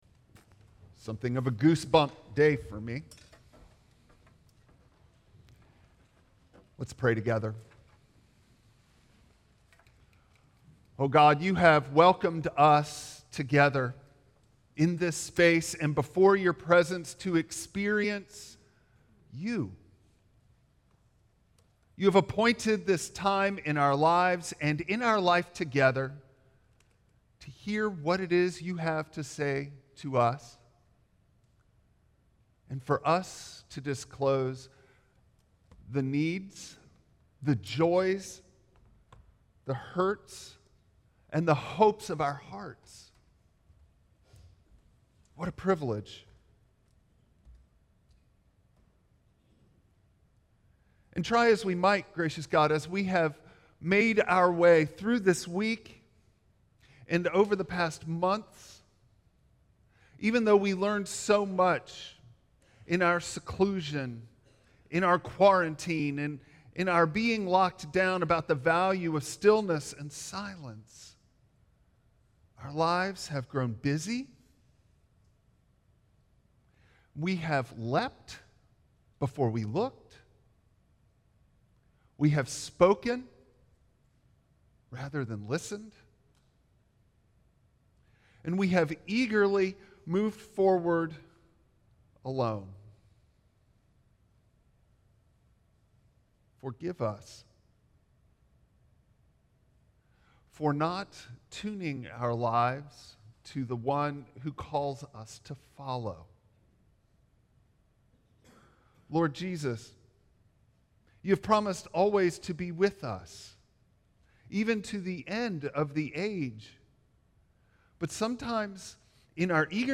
1 Peter 3:15 Service Type: Traditional Service Bible Text